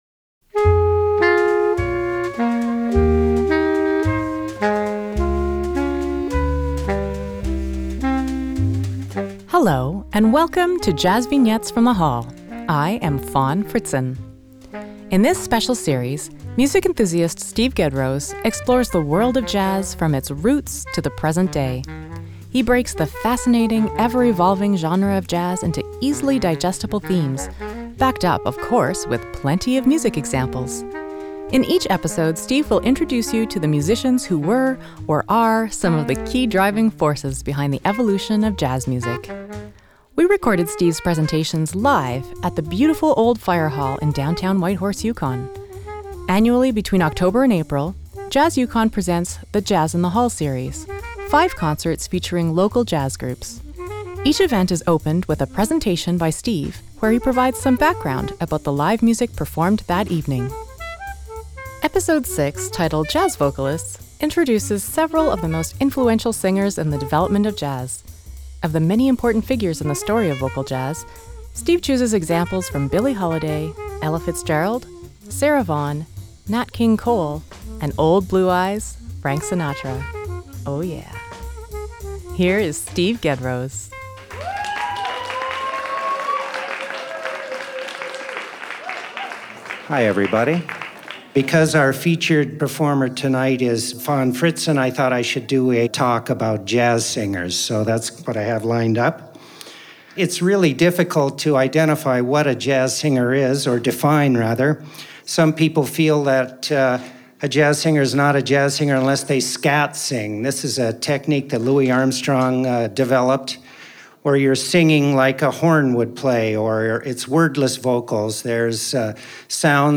JVFTH06JazzVocalists.mp3 57,845k 256kbps Stereo Comments
JVFTH06JazzVocalists.mp3